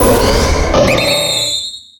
Cri de Méga-Gallame dans Pokémon Rubis Oméga et Saphir Alpha.